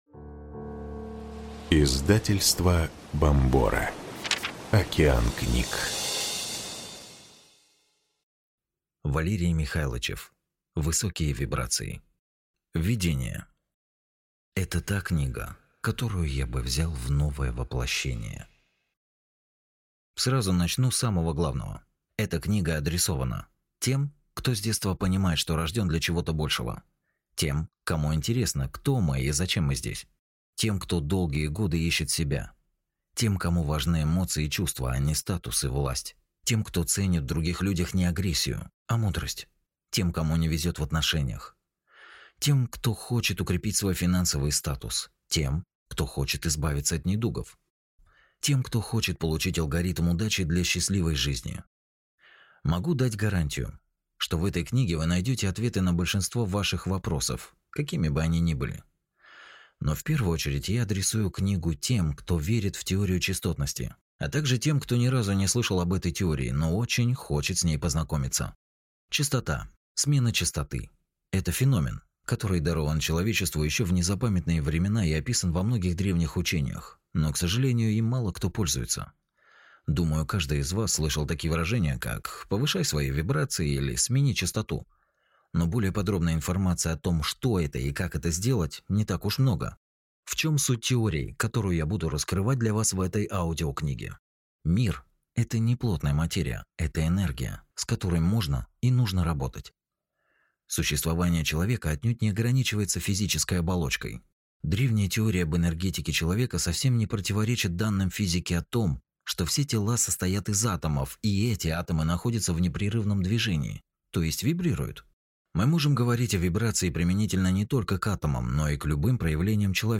Аудиокнига Высокие вибрации. Книга о работе над собой для положительных изменений в жизни | Библиотека аудиокниг